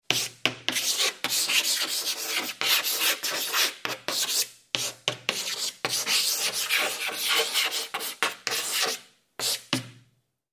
جلوه های صوتی
دانلود صدای گچ 2 از ساعد نیوز با لینک مستقیم و کیفیت بالا
برچسب: دانلود آهنگ های افکت صوتی اشیاء دانلود آلبوم صدای کشیدن گچ روی تخته سیاه از افکت صوتی اشیاء